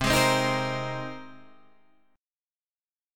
Cm7#5 chord